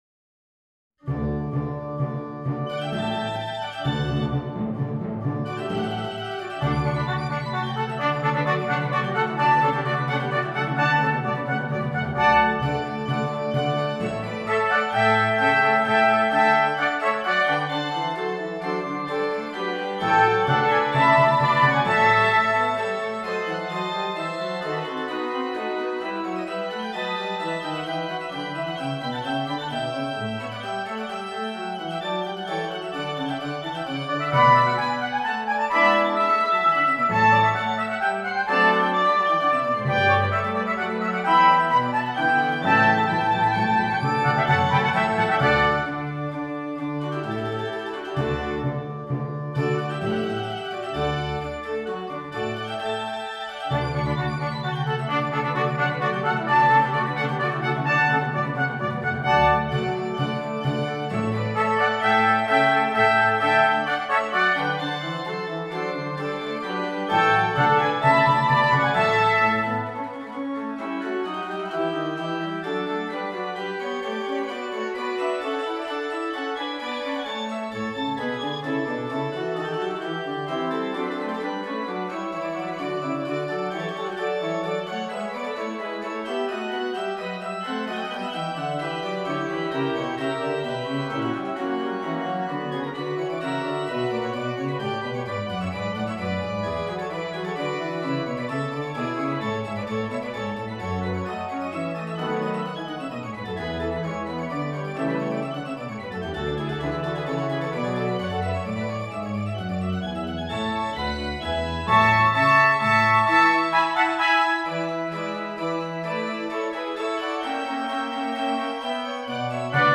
3 Trumpets and Keyboard